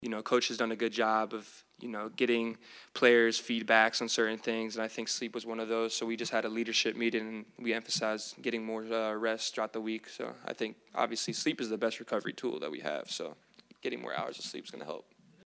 Gators wide receiver Ricky Pearsall said there is a renewed  focus on sleep as a big change to their road woes.